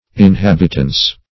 Inhabitance \In*hab"it*ance\, Inhabitancy \In*hab"it*an*cy\, n.